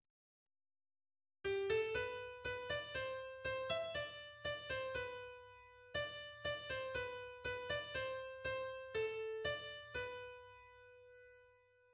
our ear will connect the first tone, G, with the B on the first quarter of measure 1 as the third of G.
Likewise, it will connect that G with the D on the first quarter of measure 2 as its fifth.
A scale-step triad is designated by an uppercase Roman numeral representing the scale degree of the root, much as in traditional "harmonic analysis" (see chord progression).[2] Thus, in the above example (which is in G major), the G major triad that Schenker claims we perceive through the first two measures would be labelled "I".